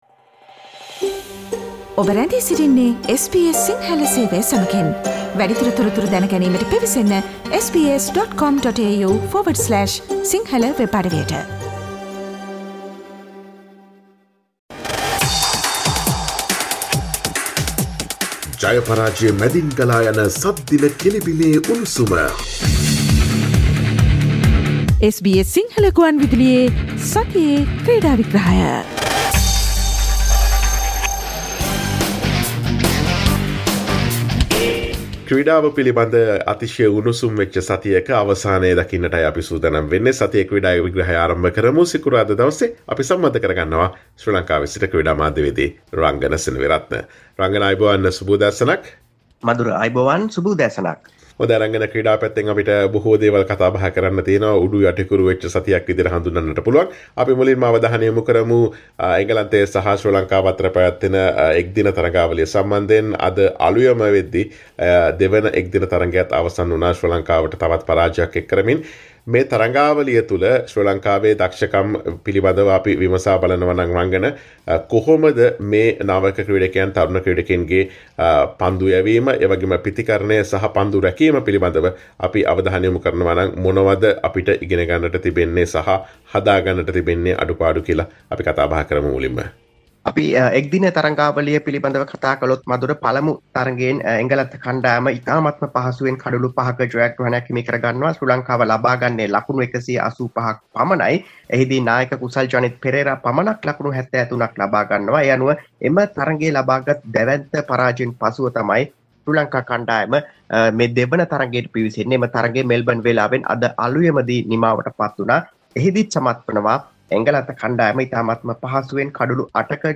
SBS Sinhala Sports Wrap